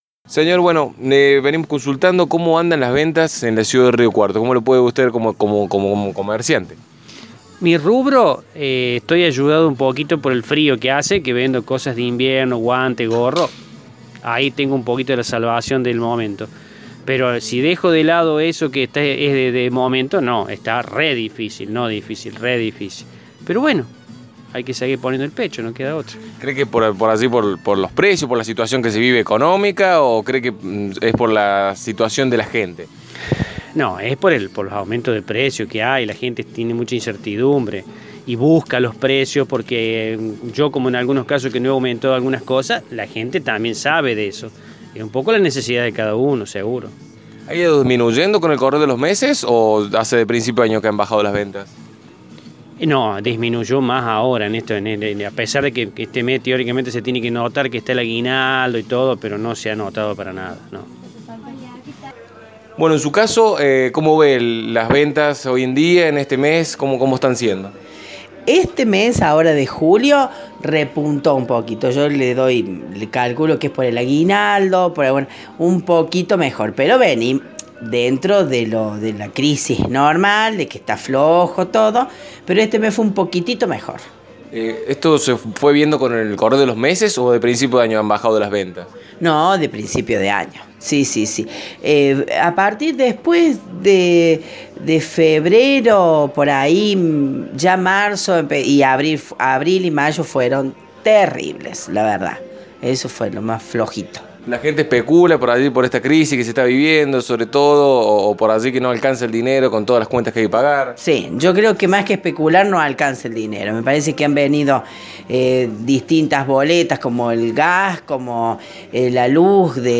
FM Eco dialogó con comerciantes de la ciudad, quienes expresaron su preocupación por la acuciante situación económica y la marcada caída en las ventas.
Aquí los testimonios obtenidos: